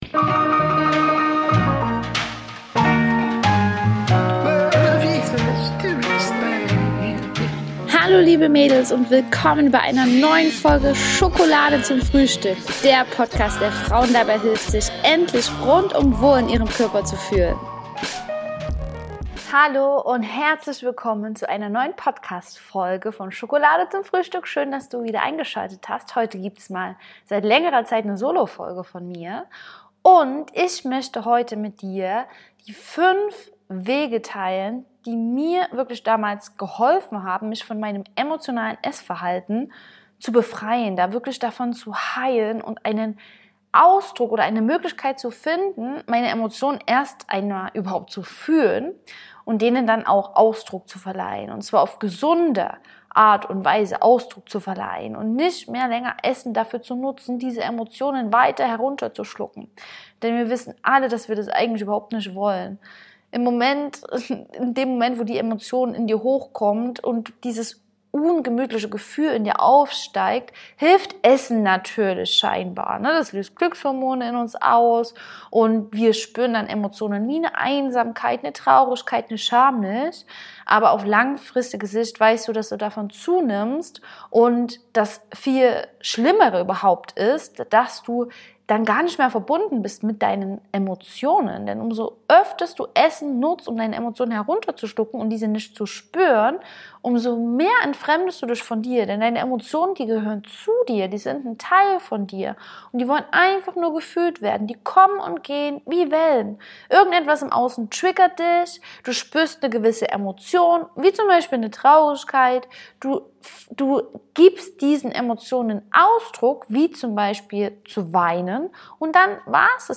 Wenn wir Angst vor unseren eigenen Emotionen haben und diese weiter mit Essen herunterschlucken, werden wir nicht nur krank und dick, sondern verlieren auch immer mehr die Verbindung zu uns selbst! Deswegen teile ich in der heutigen Solofolge 5 Wege mit dir, ab jetzt besser mit deinen Emotionen umzu...